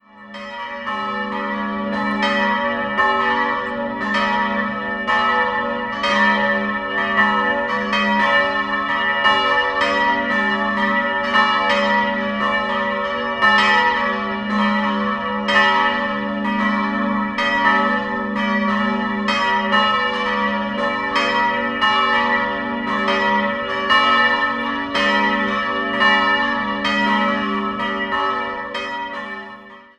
Die Ausstattung ist barock. 3-stimmiges Gloria-Geläut: as'-b'-des'' Die beiden kleineren Glocken wurden von Lotter im Jahr 1952 gegossen, die große stammt aus der Nürnberger Schule und entstand im 15. Jahrhundert.